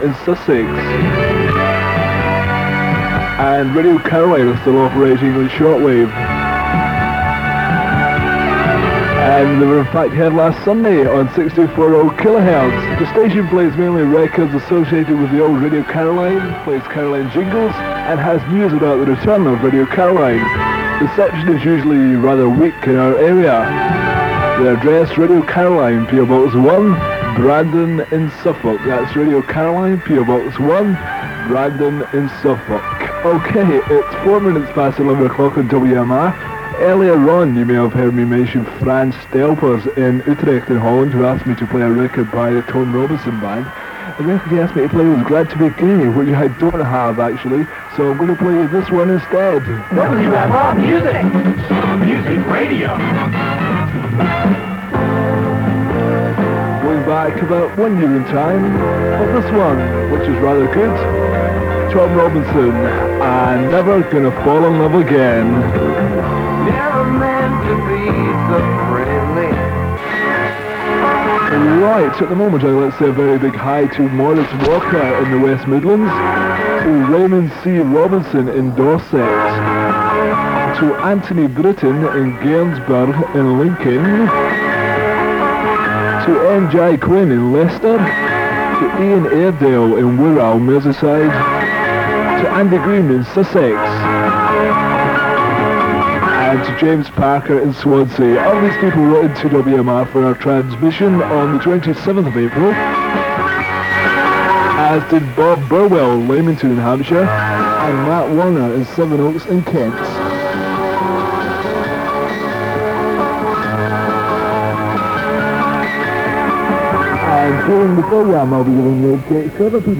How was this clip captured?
There seemed to be an issue with the broadcast tape chewing up on site during the middle of the recording, although it did recover. As received in Leeds on 7340kHz. 8MB